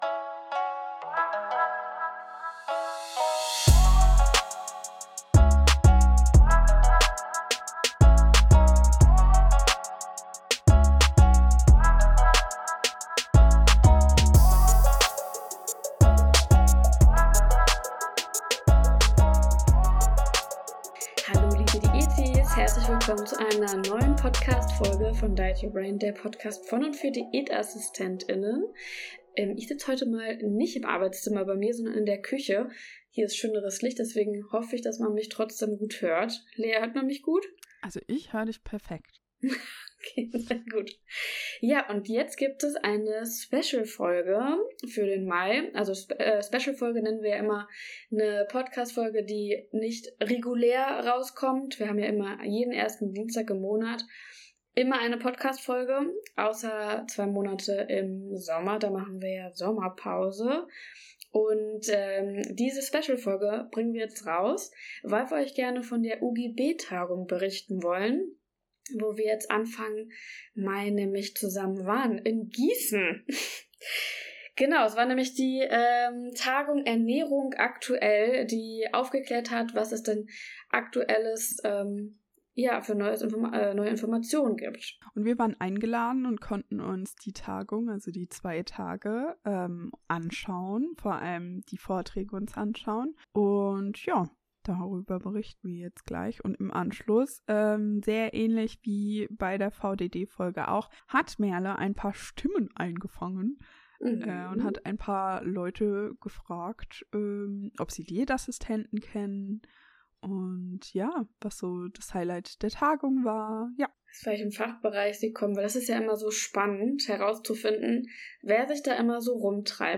Beschreibung vor 1 Jahr Auf der Fachtagung der UGB für Ernährung aktuell haben wir nicht nur Wissen gesammelt, sondern auch inspirierende Menschen getroffen! Wir haben kurze Interviews geführt, um herauszufinden, wer diese leidenschaftlichen Teilnehmenden sind und was sie antreibt. Von Diätassistentinnen, Ernährungswissenschaftlerinnen bis hin zu Gesundheitsberater*innen – sie alle teilen die gleiche Leidenschaft für eine gesunde Lebensweise.